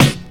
Tuned drums (C# key) Free sound effects and audio clips
• Rich Lows Steel Snare Drum Sound C# Key 89.wav
Royality free snare single hit tuned to the C# note. Loudest frequency: 1603Hz
rich-lows-steel-snare-drum-sound-c-sharp-key-89-8UJ.wav